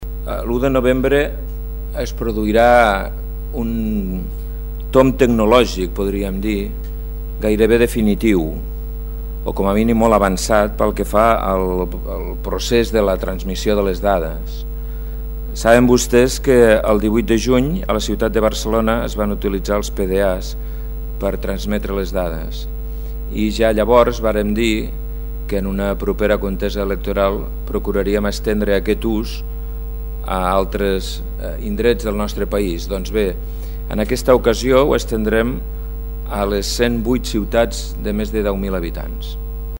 TALL DE VEU: “